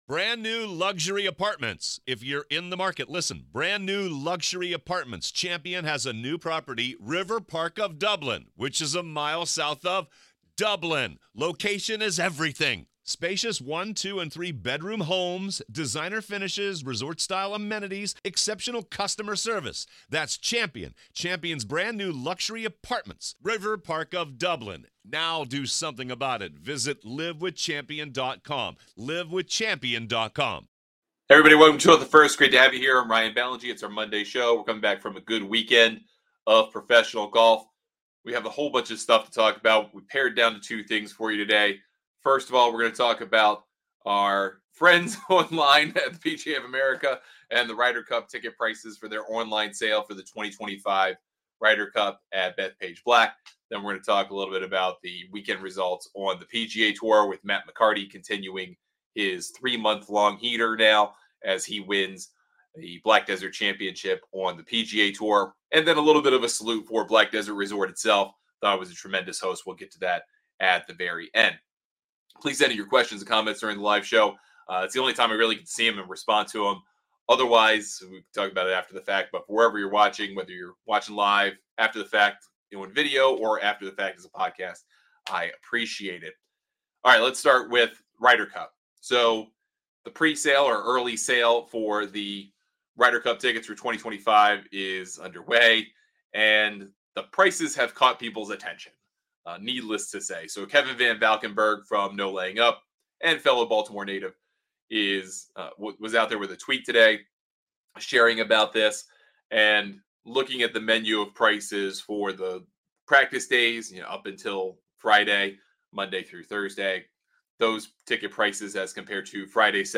On today's LIVE show, we look at the 2025 Ryder Cup ticket prices that are very controversial, as well as Matt McCarty's big win at Black Desert on the PGA Tour.